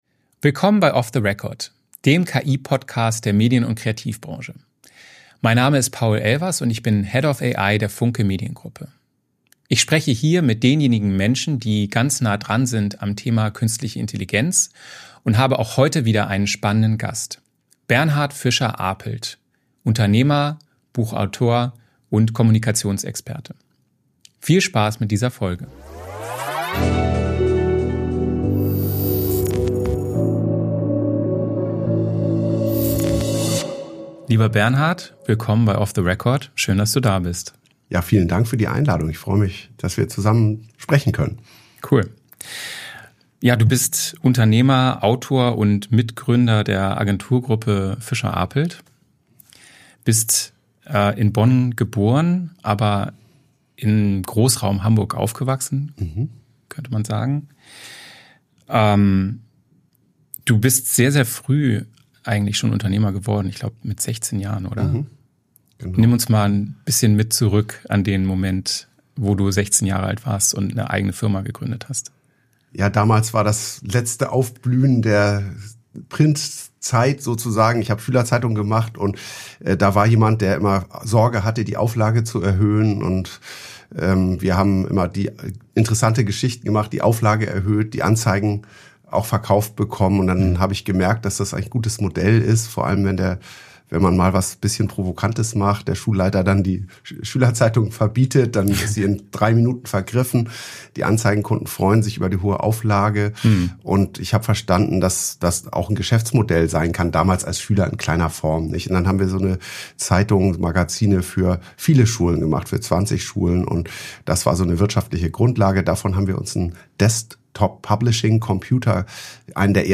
Ein Gespräch über narrative Strategien, praktische KI-Anwendungen und realistische Zukunftsvisionen.